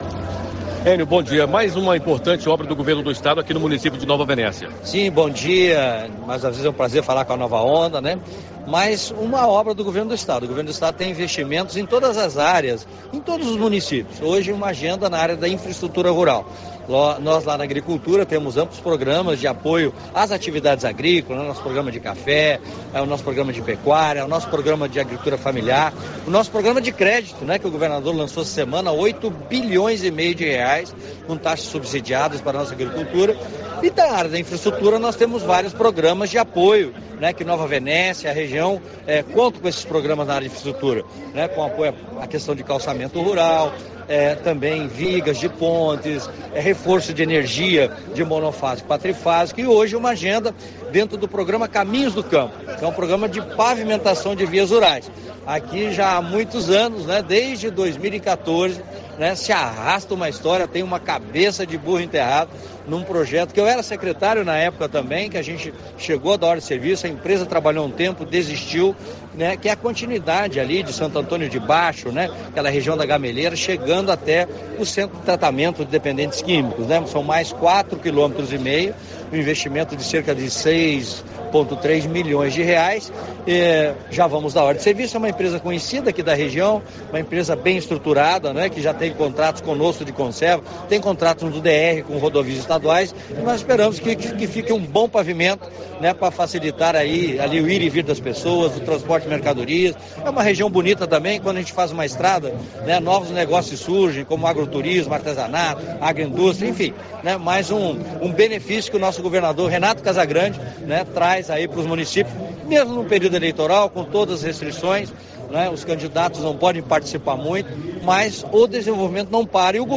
Em entrevista a Rádio Nova Onda o secretário de agricultura, Enio Bergoli, falou da obra.